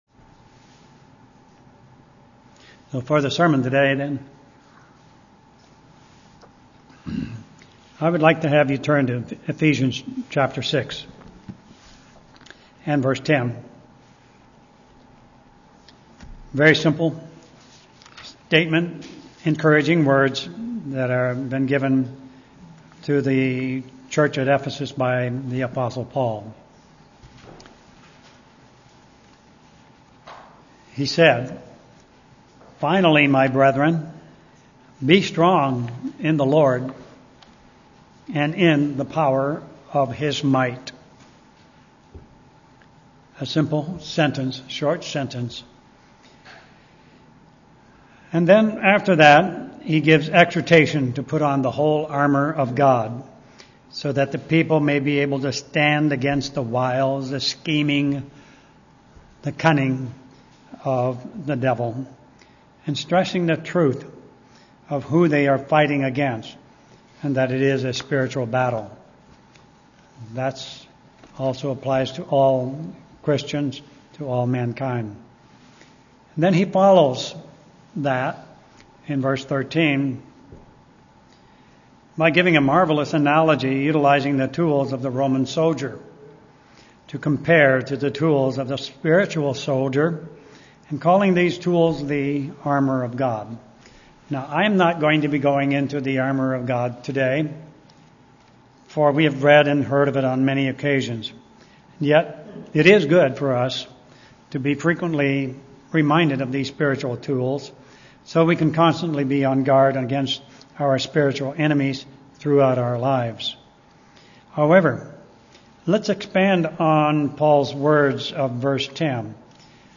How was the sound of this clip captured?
Given in Olympia, WA